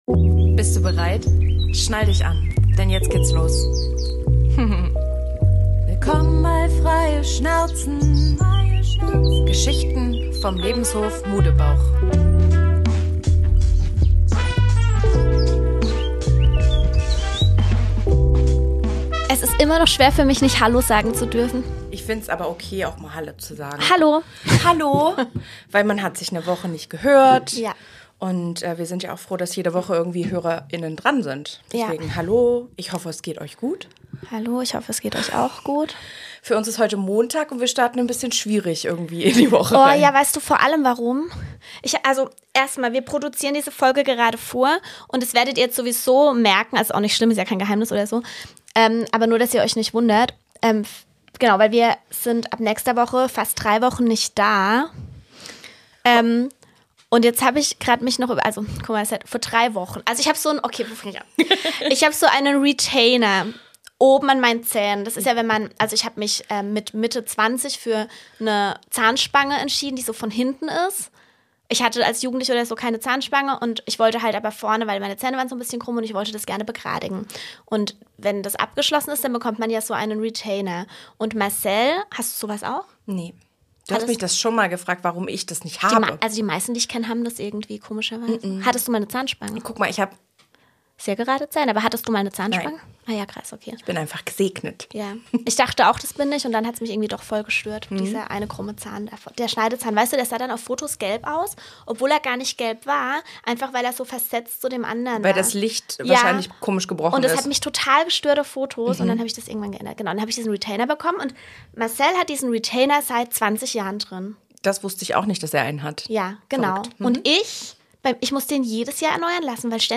Es wird mal wieder Zeit für einen richtig guten Mädelstalk, ganz nach unserem Motto: Frei Schnauze.